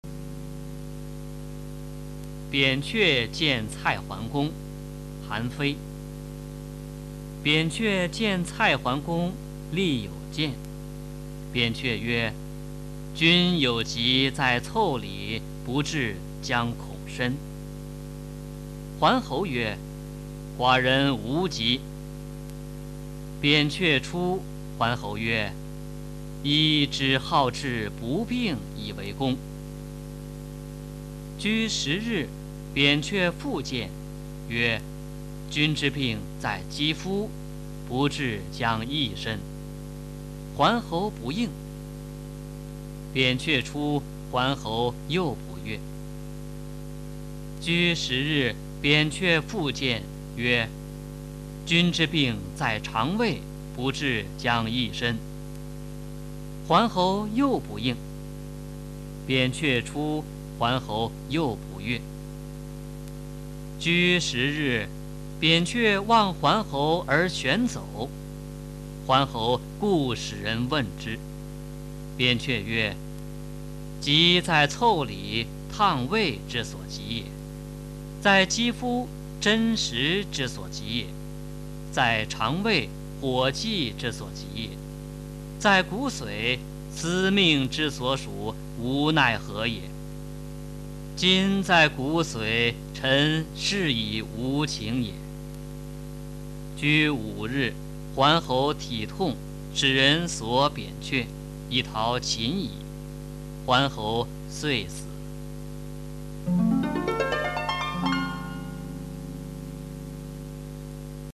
《扁鹊见蔡桓公》原文和译文（含赏析、朗读）　/ 佚名